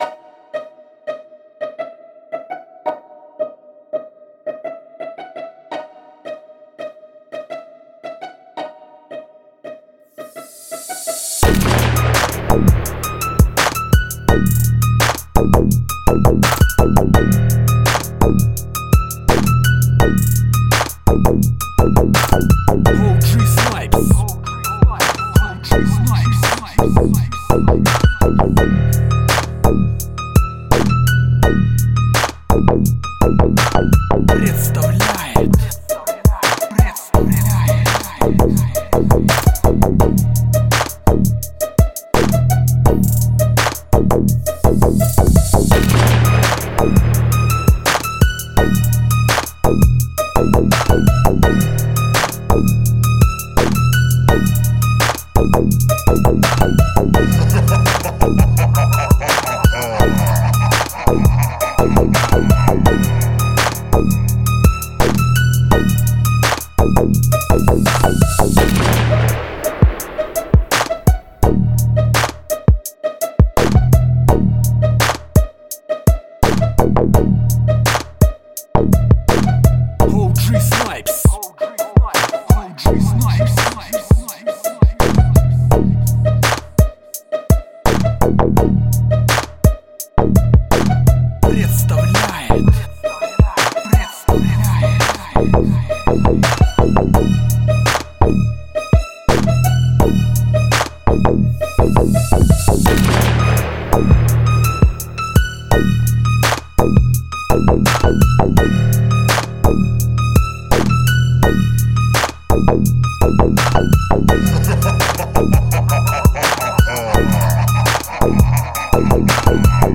Vato Chicano Rap Beat